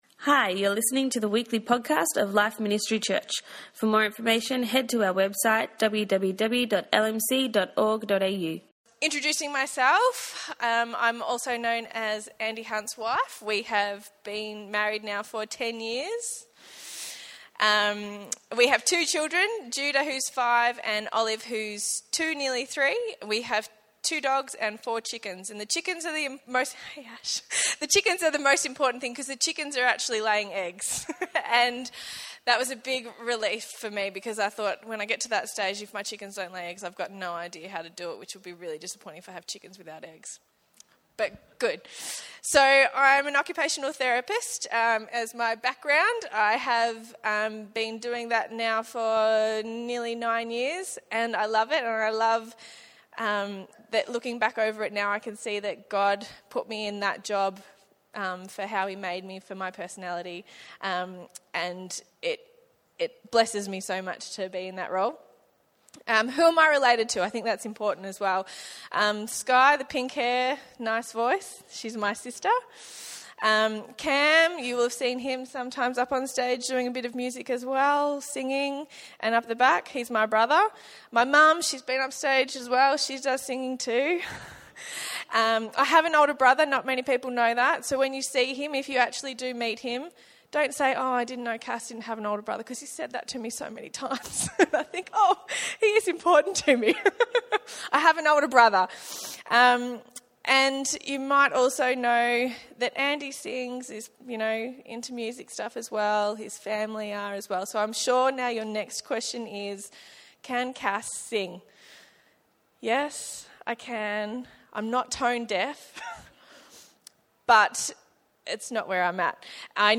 My Testimony